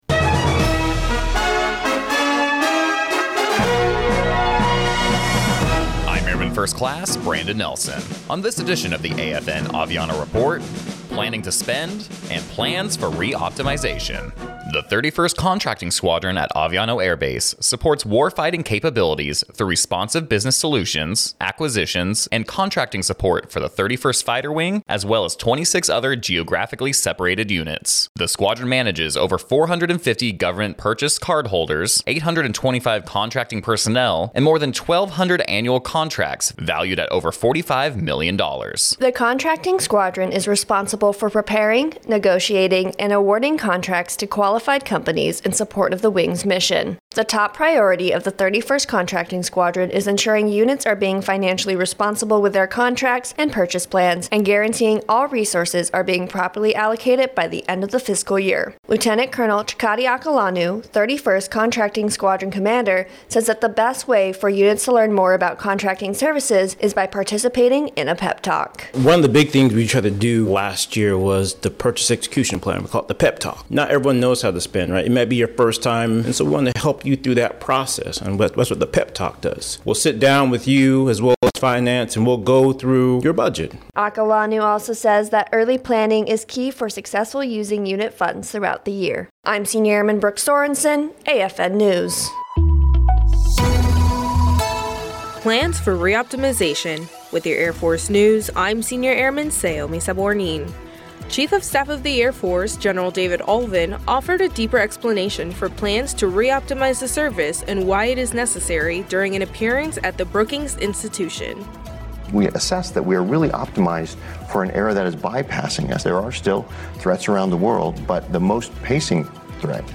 American Forces Network (AFN) Aviano radio news reports on the 31st Contracting Squadron at Aviano Air Base, and their role helping units properly use annual funding.